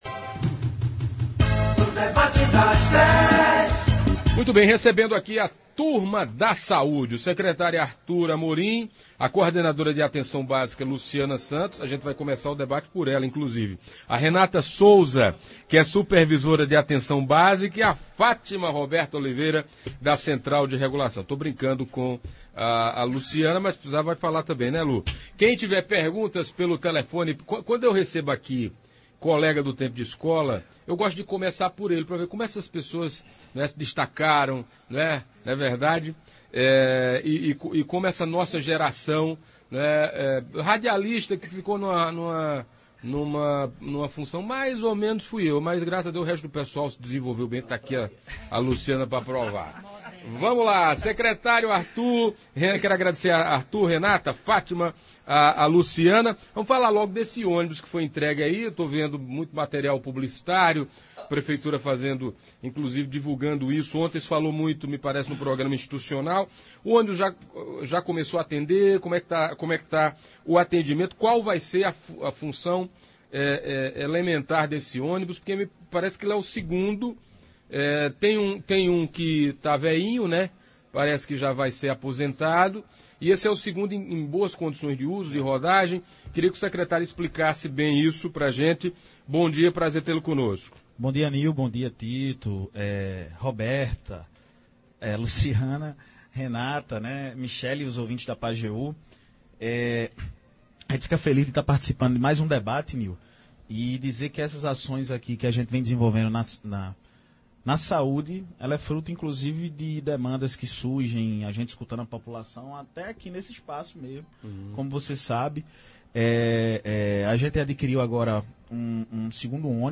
Os ouvintes e internautas da Pajeú aproveitaram para fazer seus questionamentos e tirar várias dúvidas. Principalmente em relação à marcação de consultas e exames.